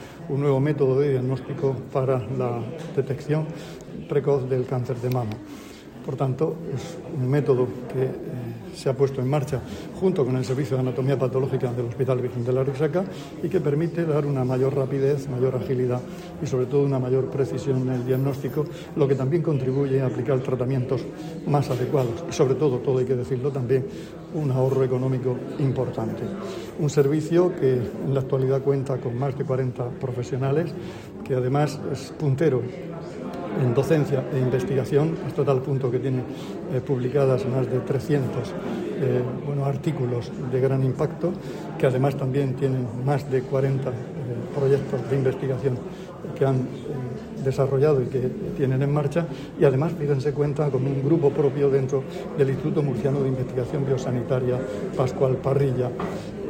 Declaraciones del consejero de Salud, Juan José Pedreño, sobre la puesta en marcha  de un nuevo método diagnóstico para detectar el cáncer de mama por parte del Servicio regional de Inmunología.